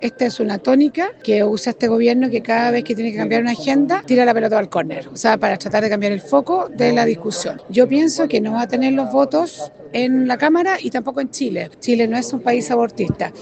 Por lo mismo, se considera la dificultad de que la iniciativa avance. Al respecto se refirió la diputada y jefa de bancada de Renovación Nacional, Ximena Ossandón.